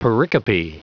Prononciation du mot pericope en anglais (fichier audio)
Prononciation du mot : pericope